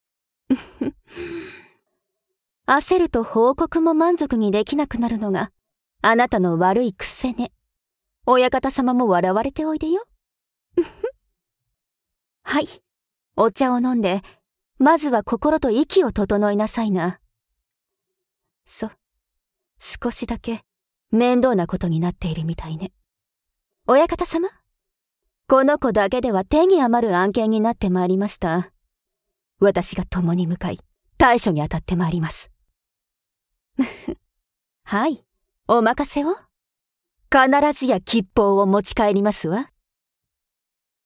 B１テイクへ選定＆セリフ間の間の調整